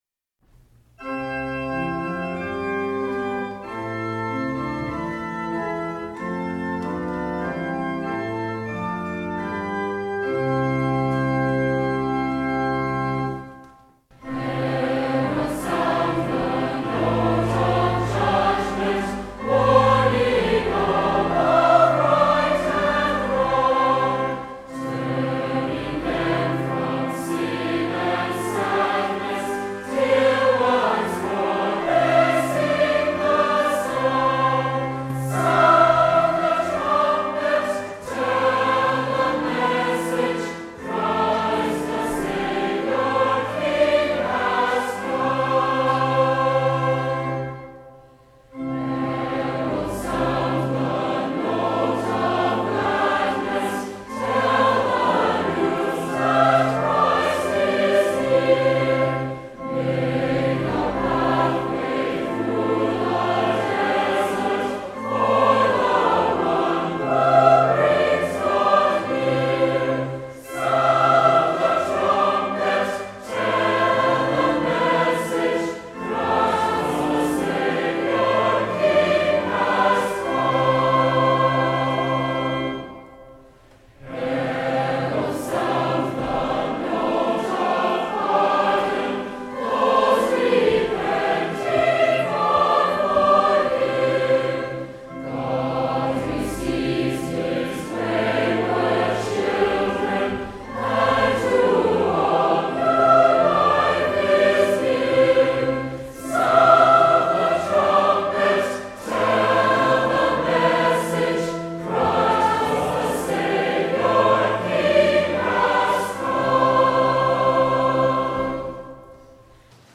Worship and Sermon audio podcasts
Podcast from Christ Church Cathedral Fredericton
WORSHIP - 10:30 a.m. The Baptism of the Lord